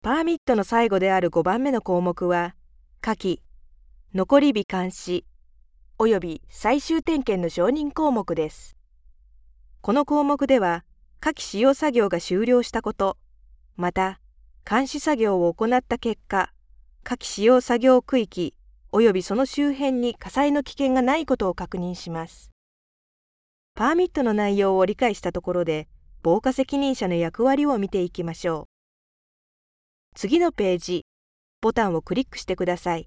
Professionelle japanische Sprecherin für TV / Rundfunk / Industrie.
Sprechprobe: Industrie (Muttersprache):